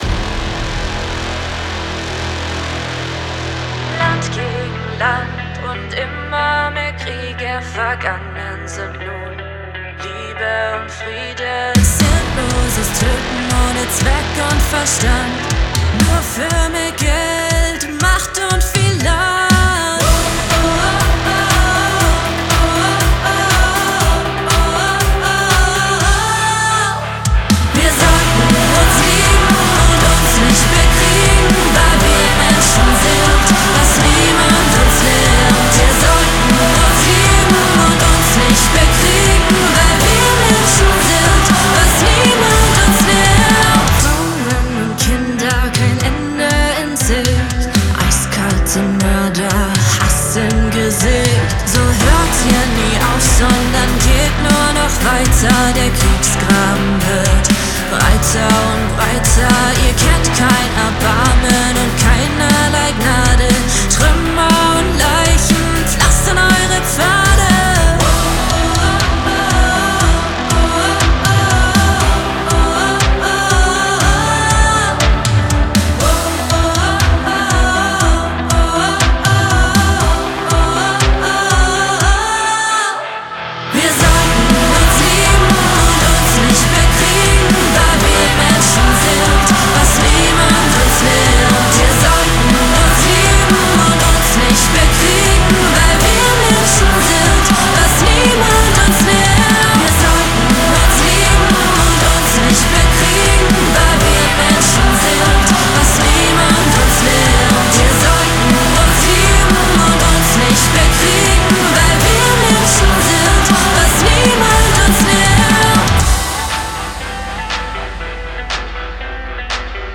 Female Fronted Metalband